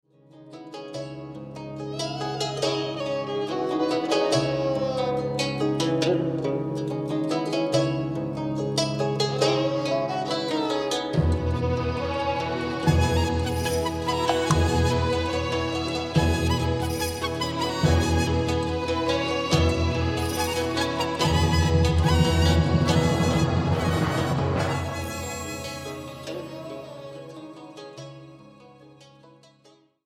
electroacoustic pedal harp, gu-cheng & more...
Recorded and mixed at the Sinus Studios, Bern, Switzerland